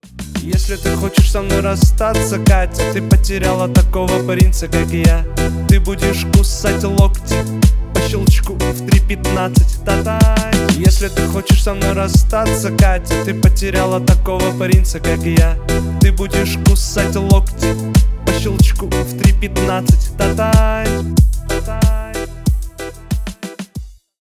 Рэп и Хип Хоп # Юмор